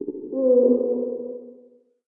PixelPerfectionCE/assets/minecraft/sounds/ambient/cave/cave8.ogg at mc116